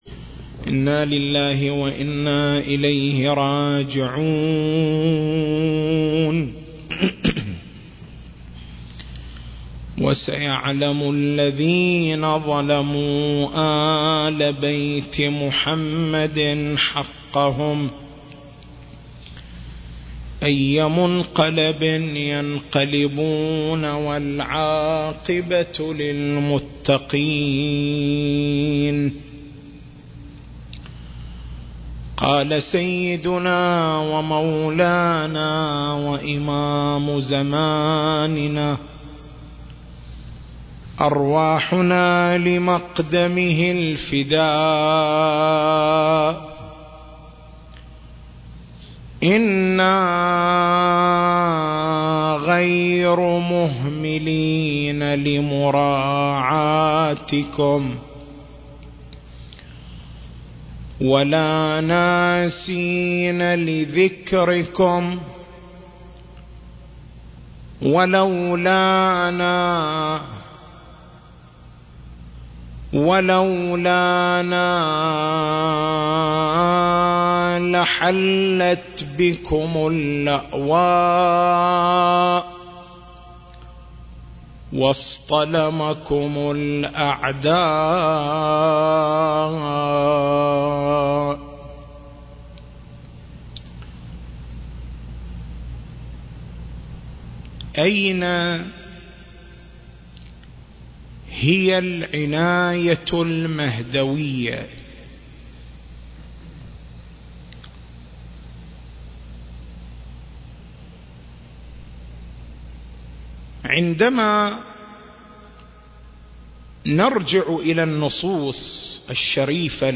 المكان: حسينية الملا عبد الله الزين - القطيف التاريخ: 2023